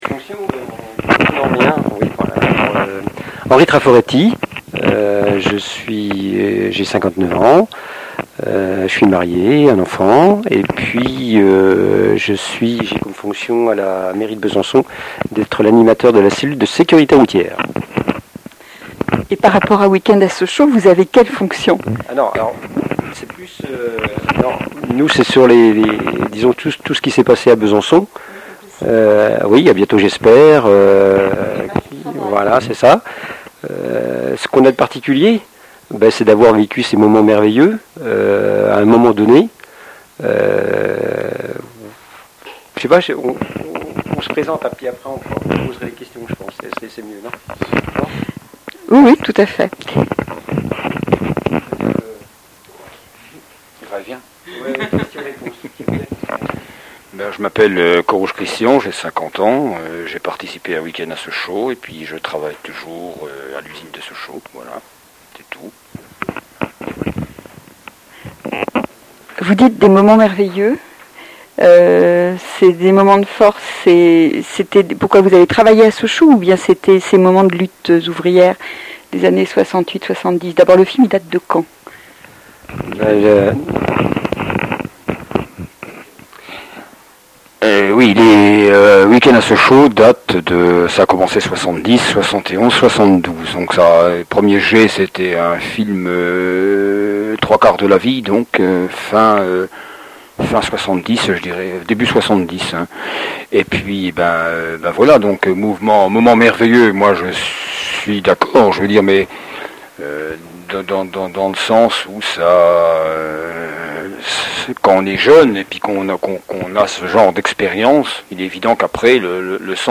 Entretiens